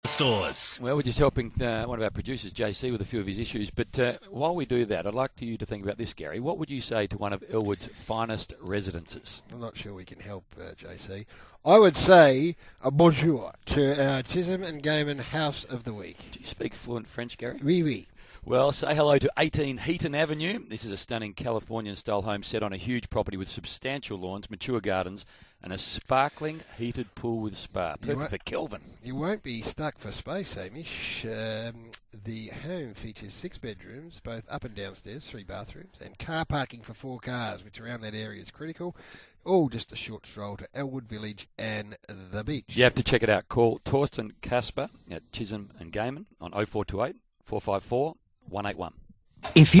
Live Read